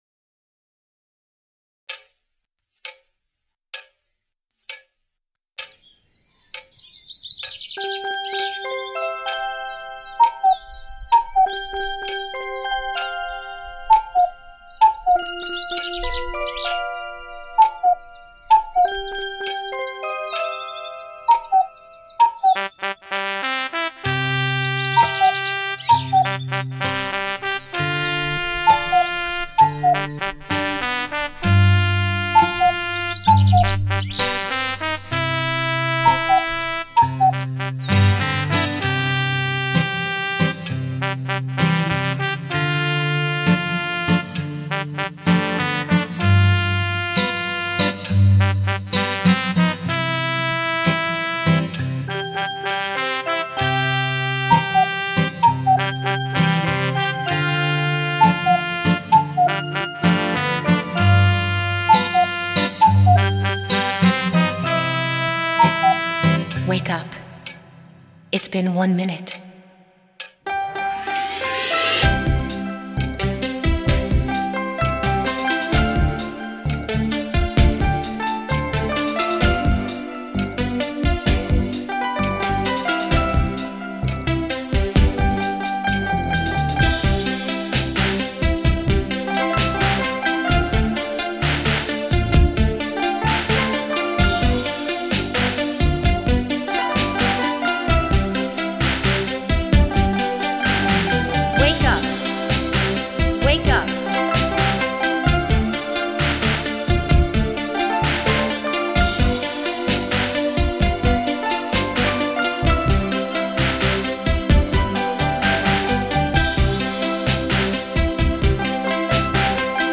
FREE alarm clock sound, loud RINGTONE for your phone